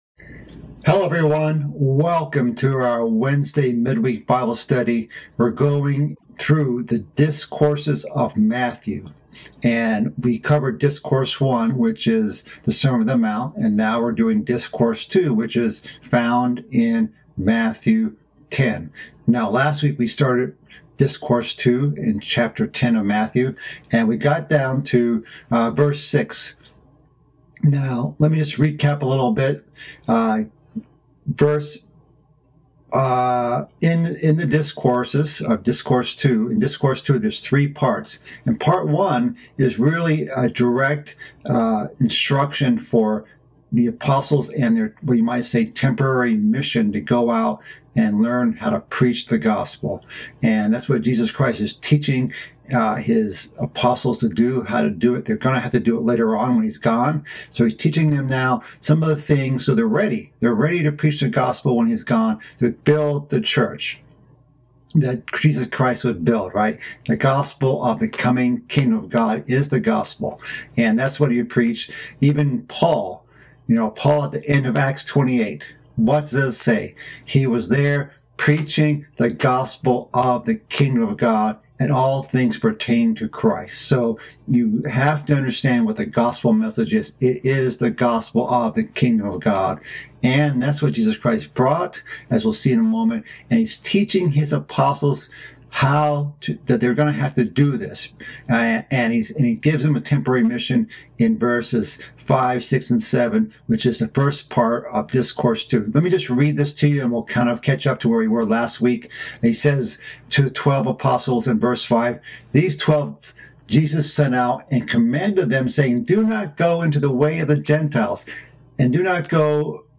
This is the second part of a mid-week Bible study series covering Christ's second discourse in the book of Matthew. This message continues in chapter 10 of Matthew, covering Christ's commands to the apostles on preaching the gospel and how to conduct themselves on their journeys.